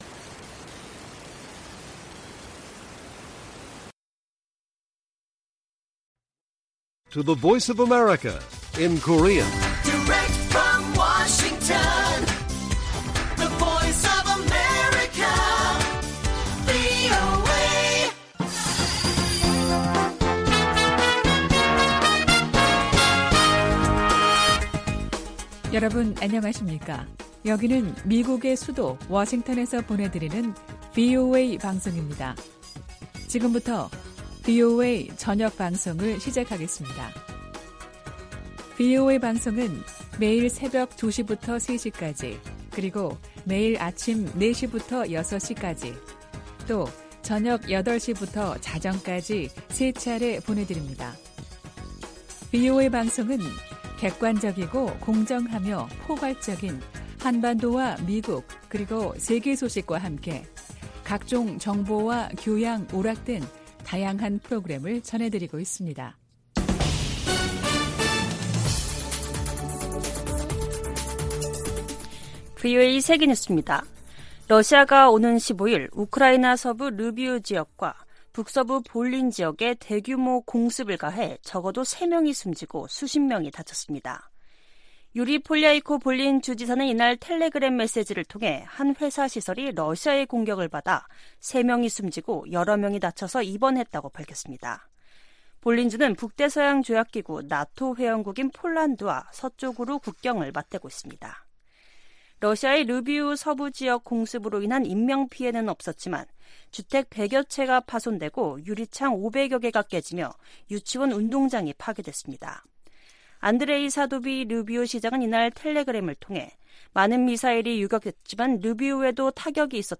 VOA 한국어 간판 뉴스 프로그램 '뉴스 투데이', 2023년 8월 15일 1부 방송입니다. 18일 캠프 데이비드 미한일 정상회의에서 3국 협력을 제도화하는 방안이 나올 것으로 전망되고 있습니다. 미 국방부는 미한일 군사훈련 정례화 문제와 관련해 이미 관련 논의가 있었음을 시사했습니다. 윤석열 한국 대통령은 광복절 경축사에서 북한에 대한 ‘담대한 구상’ 제안을 재확인하면서 압도적 힘에 의한 평화 구축을 강조했습니다.